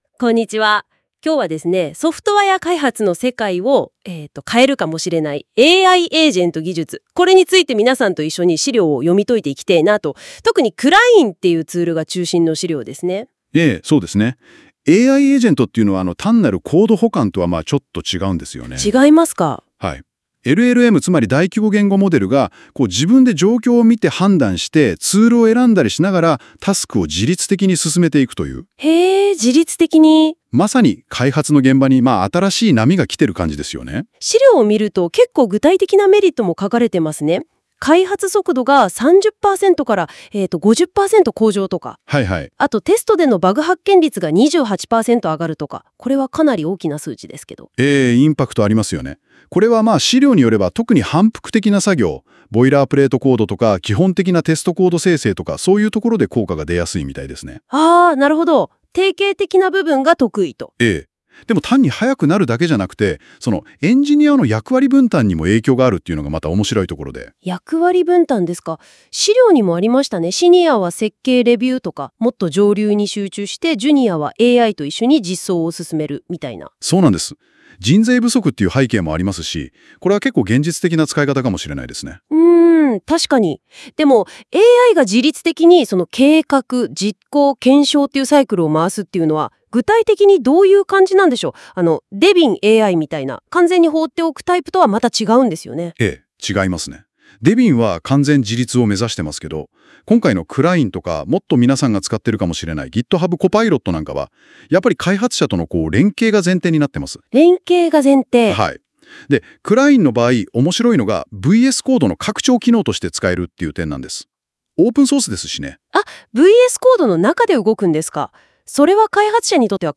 • ・自然なトーンのAI音声（TTS）でWAV化
3. ３、数分後、AIナレーションのWAVファイルが出力完了！
• ・まるでポッドキャストのような自然な会話調
A. TTS（Text-to-Speech）とは思えないほど自然で、会話形式の読み上げがとても聴きやすいです。